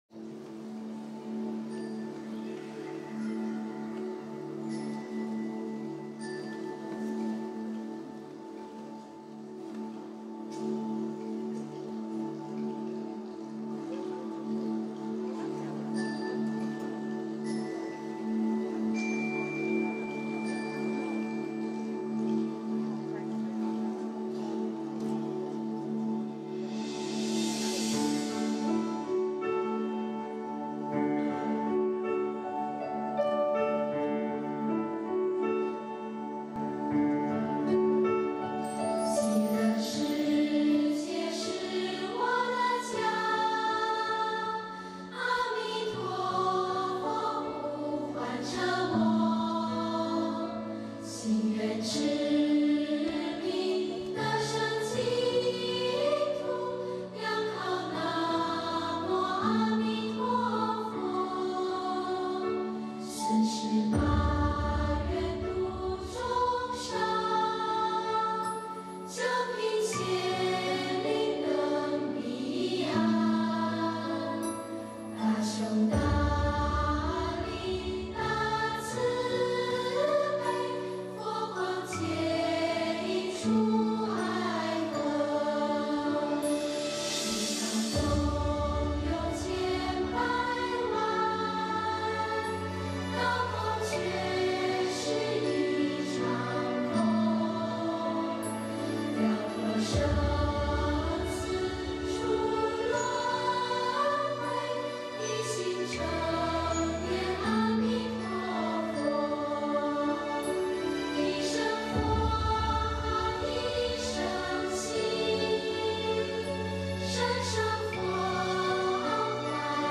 音频：《极乐世界是我家》新加坡一叶一菩提千人素食分享会！